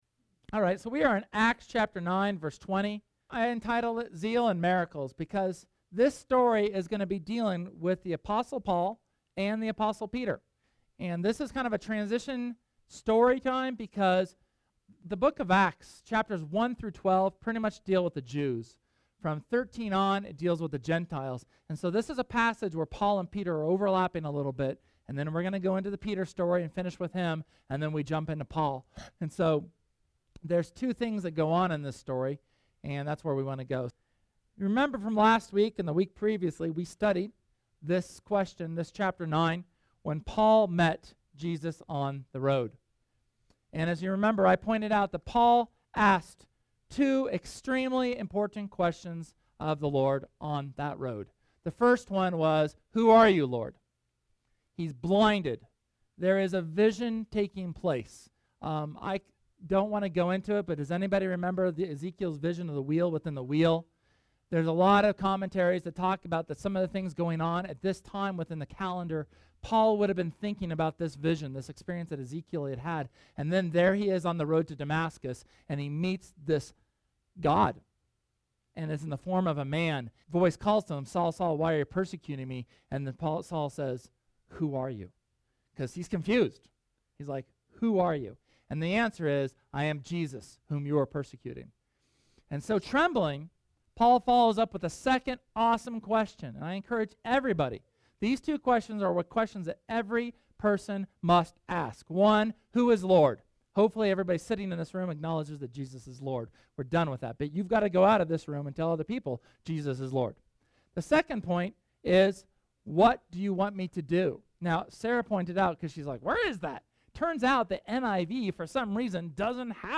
SERMON: Zeal & Miracles